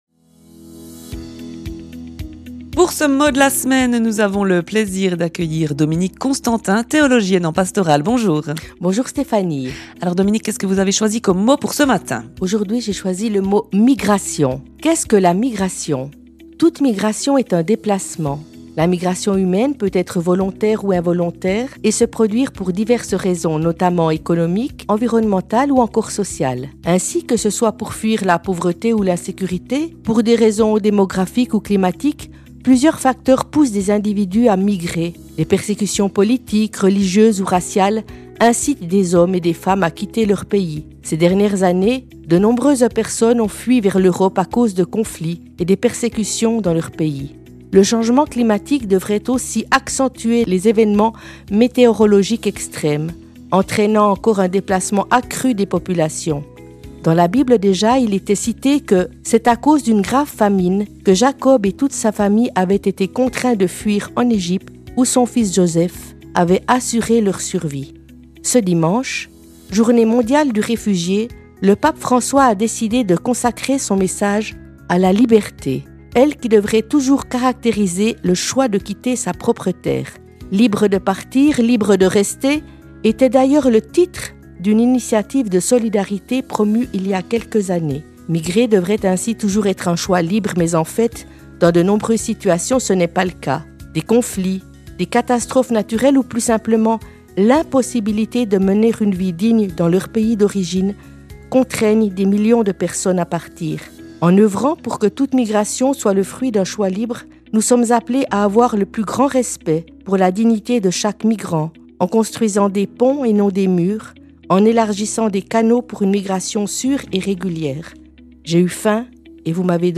La chronique est diffusée le dimanche matin à 8h45 sur les ondes de RFJ.